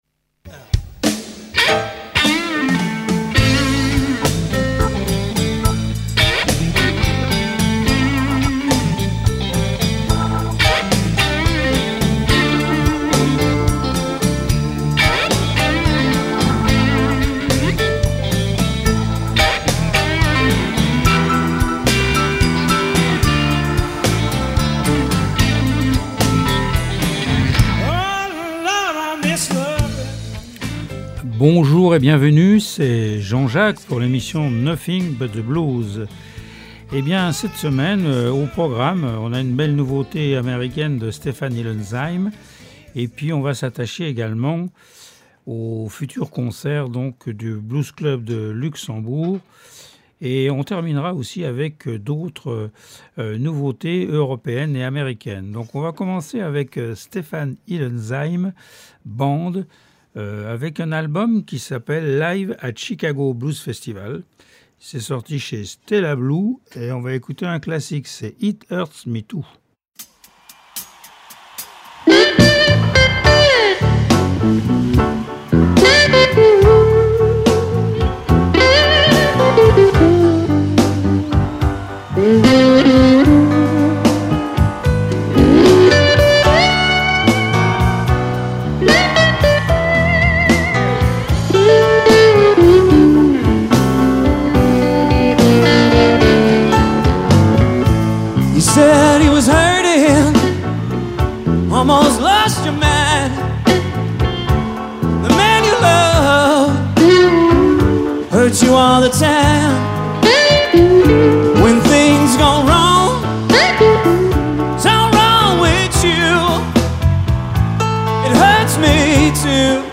Du traditionnel au blues rock actuel.
L’émission offre un espace aux musiciens Lorrains et à la particularité de présenter de la musique en Live et des interviews.